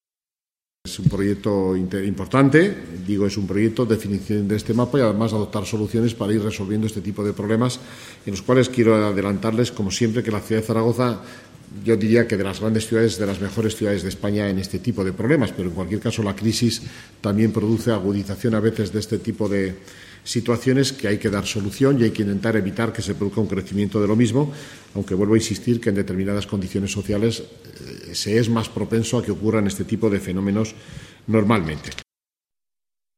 El vicealcalde, Fernando gimeno, ha comentado al respecto: